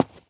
footstep_concrete_walk_02.wav